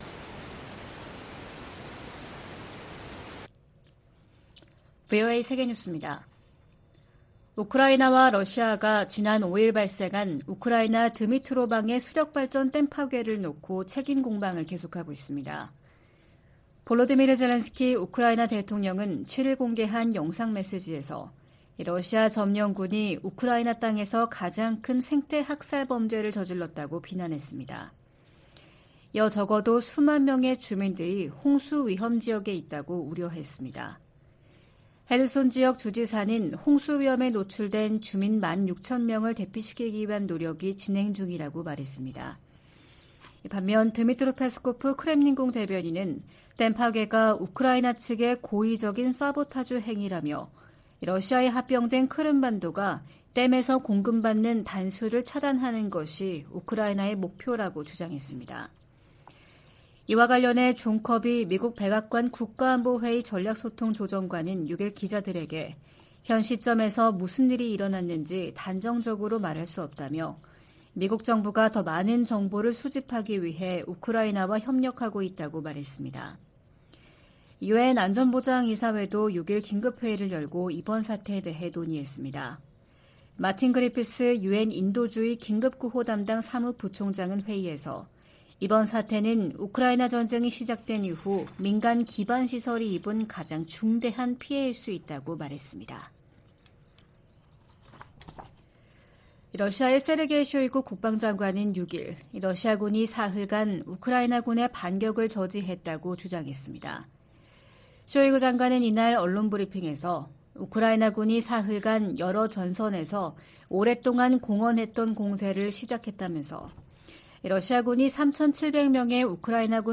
VOA 한국어 '출발 뉴스 쇼', 2023년 6월 8일 방송입니다. 한국이 유엔 안전보장이사회 비상임이사국으로 다시 선출됐습니다.